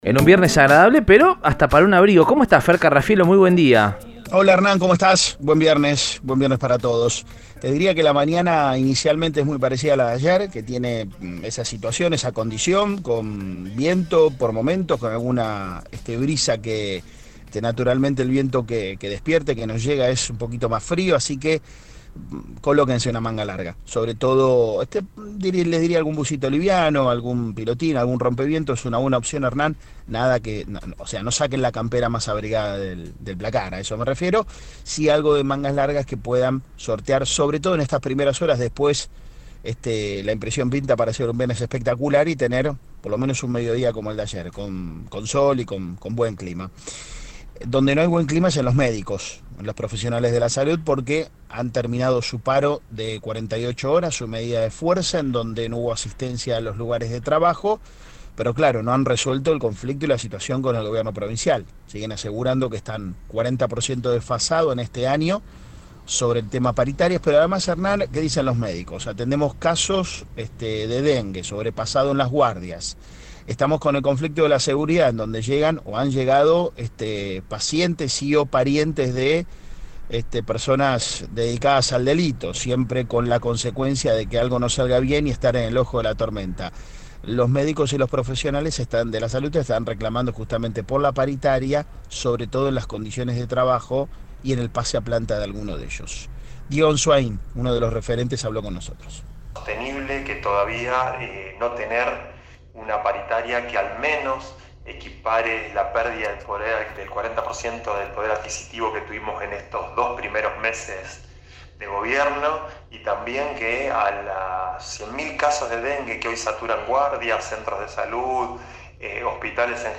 en contacto con el móvil de Cadena 3 Rosario, en Radioinforme 3.